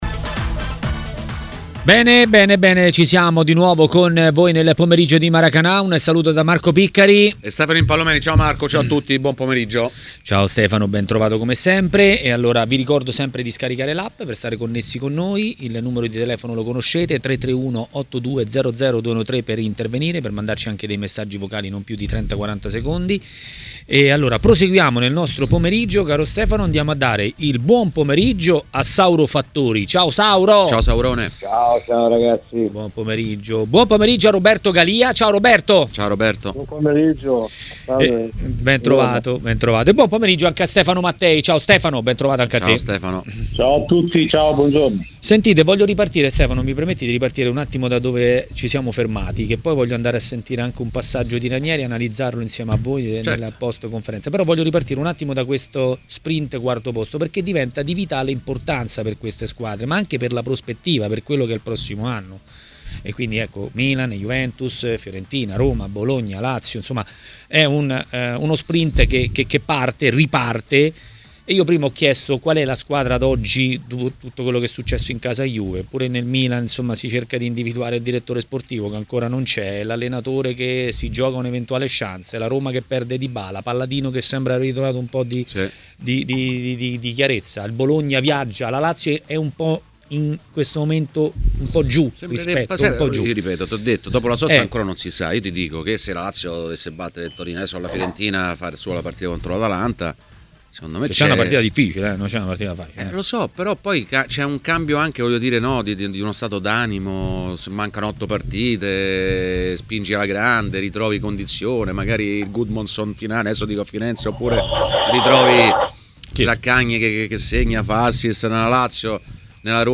L'ex calciatore Roberto Galia è intervenuto a TMW Radio, durante Maracanà.